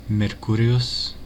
Mercury (/ˈmɜːrkjʊri/; Latin: Mercurius [mɛrˈkʊrijʊs]